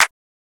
(CLAP) Cartoon Pluggz Clap.wav